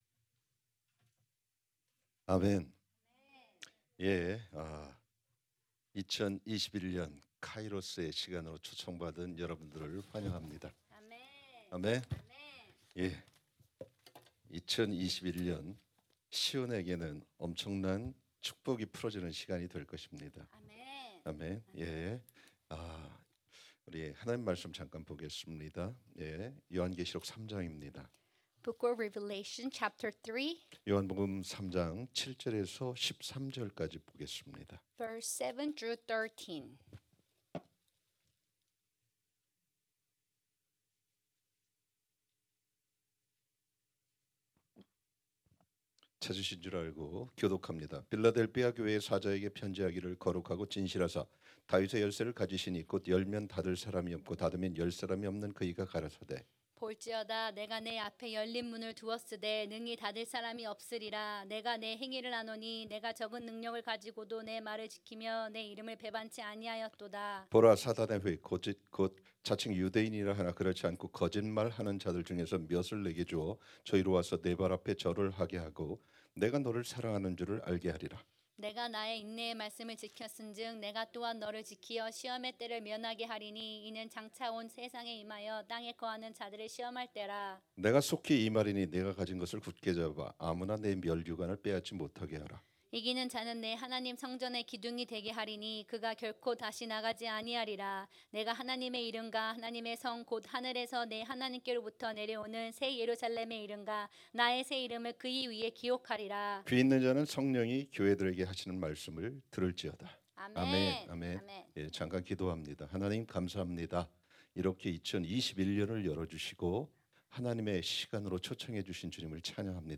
2021년 신년집회 둘째날 말씀 [계 3:7-13]